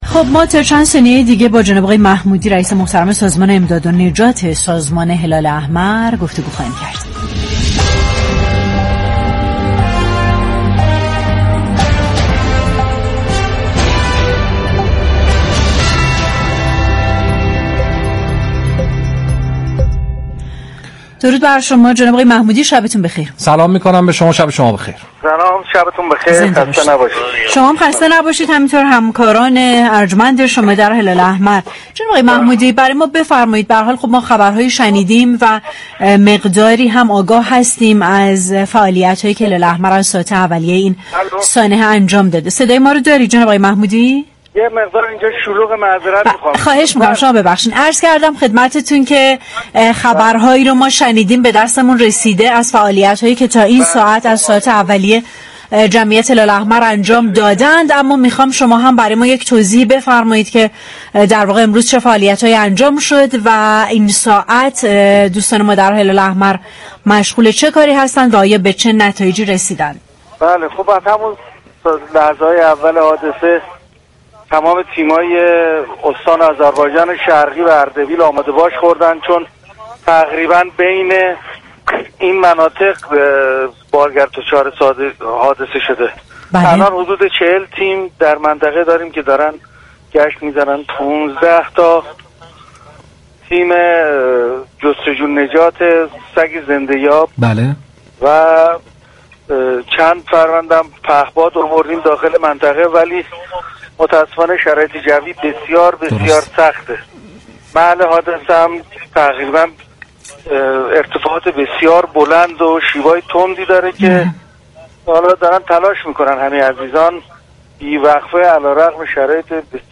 به گزارش پایگاه اطلاع رسانی رادیو تهران، بابك محمودی رئیس سازمان امداد و نجات جمعیت هلال احمر در گفت و گو با رادیو تهران اظهار داشت: از همان ابتدای وقوع حادثه تمام تیم‌های استان اردبیل و آذربایجان شرقی آماده باش هستند.